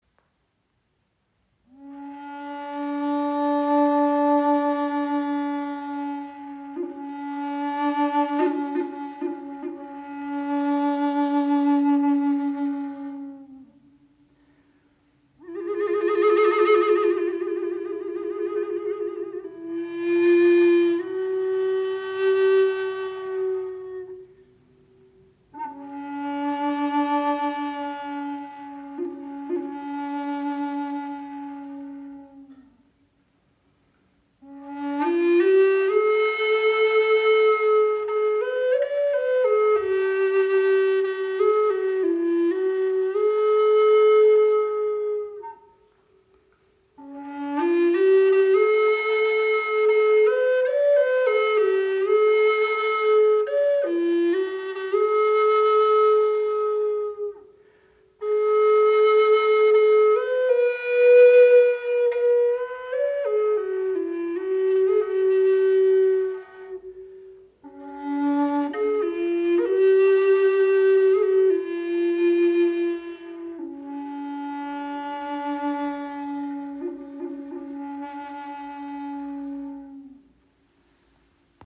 Gebetsflöte in tiefem D - 432 Hz
• Icon Bass Gebetsflöte in tiefem D mit 432 Hz
Sie schenkt Dir ein wundervolles Fibrato, kann als Soloinstrument gespielt werden oder als weiche Untermahlung Deiner Musik.